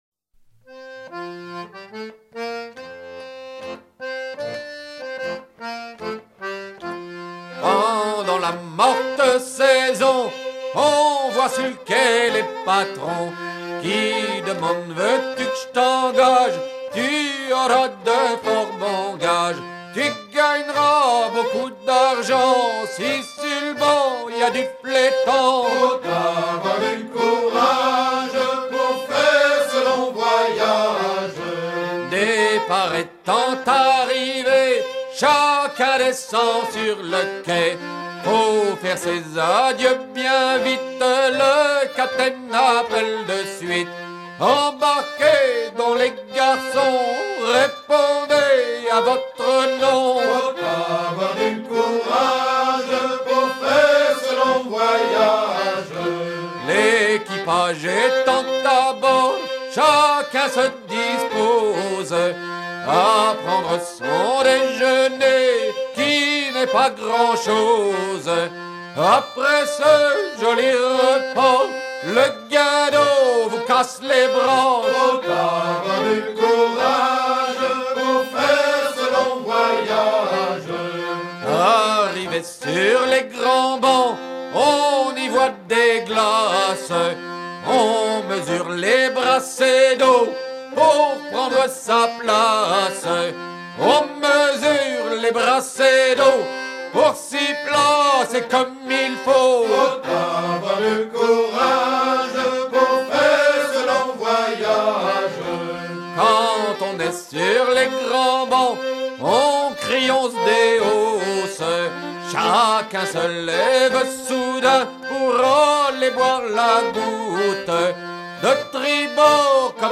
Version recueillie à Eletot (76) auprès de deux terre-neuvas embarquant à Fécamp
à virer au guindeau
Pièce musicale éditée